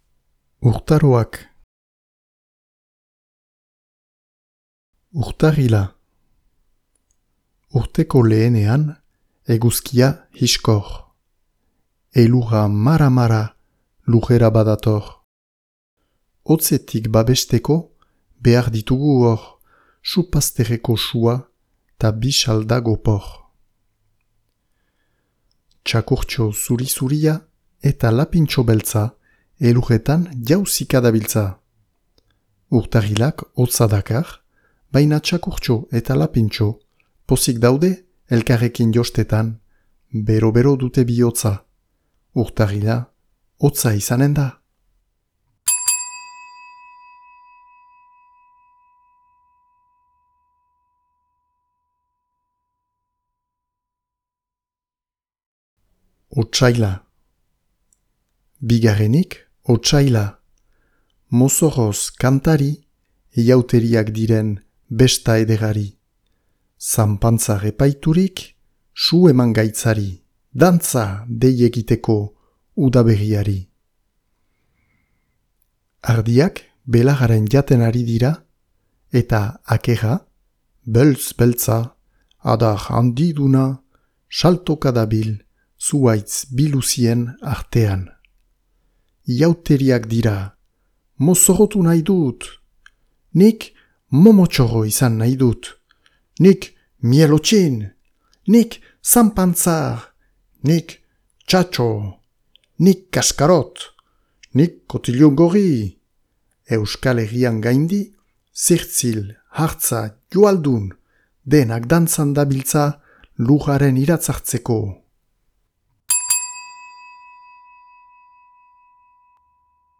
Urtaroak - Batuaz - ipuina entzungai
Ixabel Etxeberria eta Garbiñe Ubedaren albumaren testua, grabaturik.